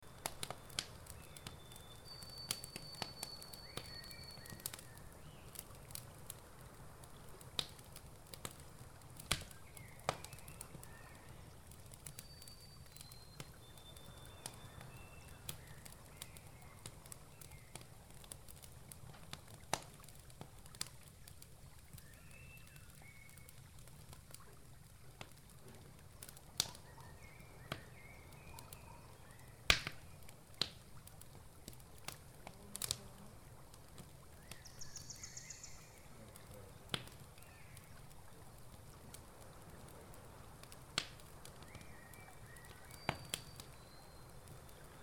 Download Camp Ambience sound effect for free.
Camp Ambience